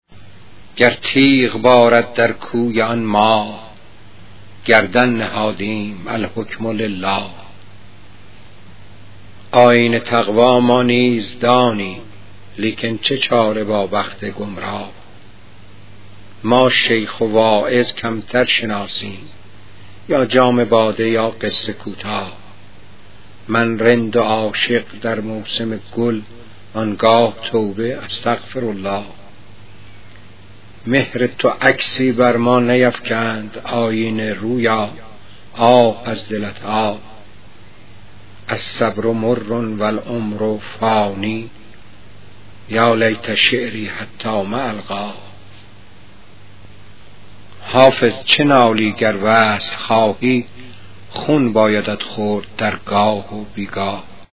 🎵 پخش صوتی غزل با صدای موسوی گرمارودی: